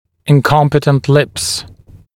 [ɪn’kɔmpɪtənt lɪps][ин’компитэнт липс]несмыкающиеся губы